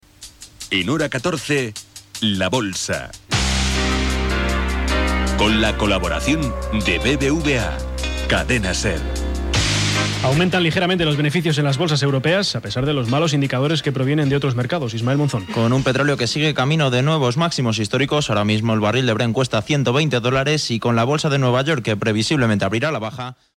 Tancament del programa, amb l'hora, equip i sintonia del programa.